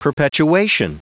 Prononciation du mot perpetuation en anglais (fichier audio)
Prononciation du mot : perpetuation